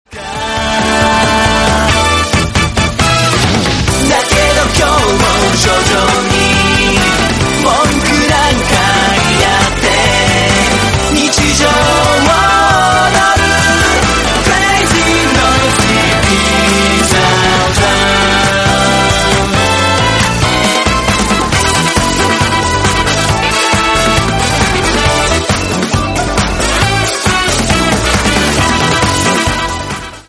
Play, download and share Crazy Noisy BZT 1 original sound button!!!!
crazy-noisy-bzt-1.mp3